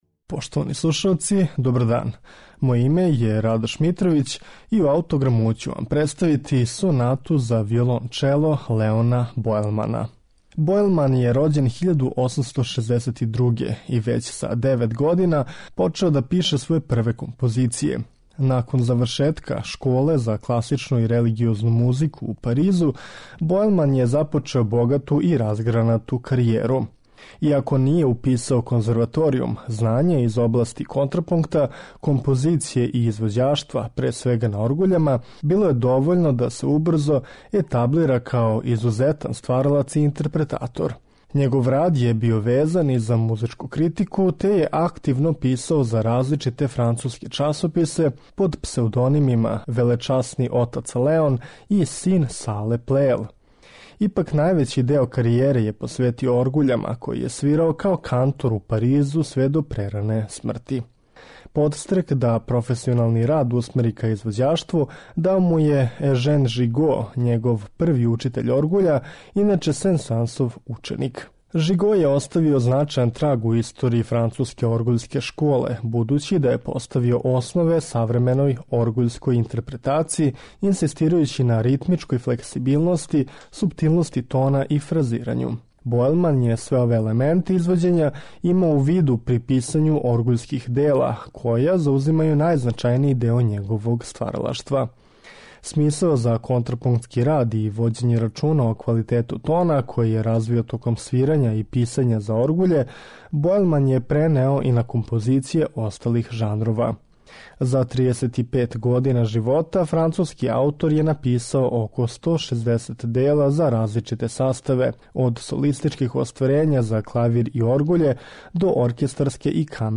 ЛЕОН БОЕЛМАН - СОНАТА ЗА ВИОЛОНЧЕЛО
Посвећена је виолончелисти Жилу Делсару и почива на постромантичарском језику, са елементима импресионизма.
виолончелисте
пијанисткиње